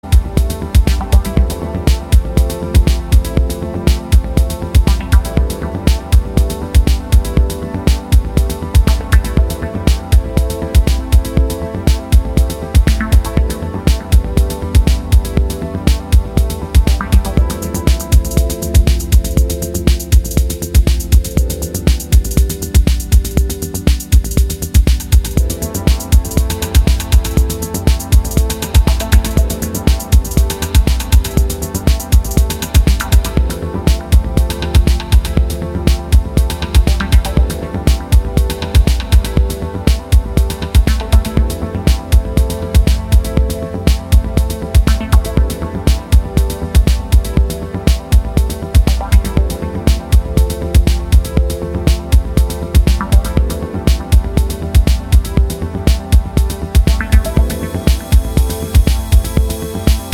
rooted in the Chicago traditions
House Chicago